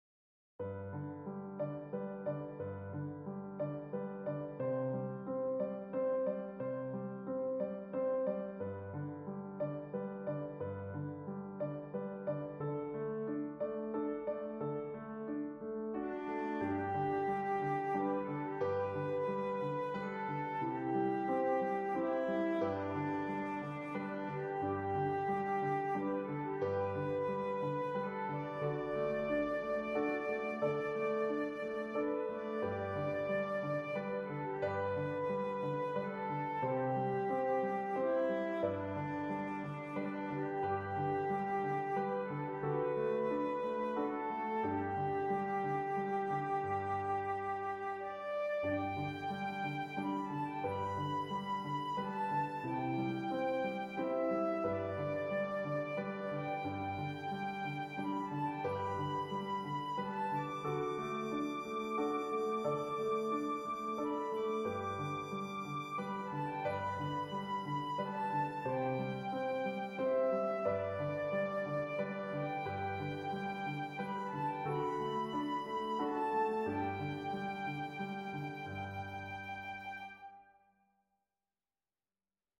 traditional folk hymn
for flute and piano